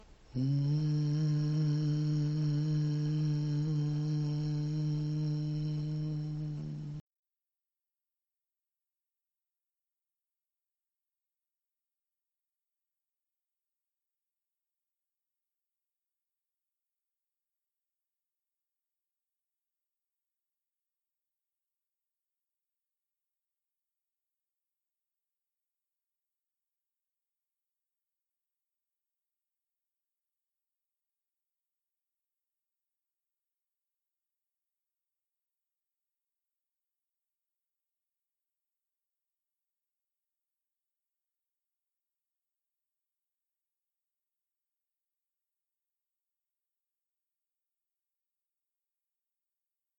An Audio Installation at Moray Art Centre
Speakers are placed at the water level of the elevated boat, playing the recordings of the hums and breaths of women rowers from Cromarty.
Wooden handmade sailing boat (facing due west towards Cromarty), pink canvas sail, aluminium scaffolding, rusted steel pool, Loch Maree water darkened with Black Isle peat, and five speakers playing the sounds of rower’s breaths and hums.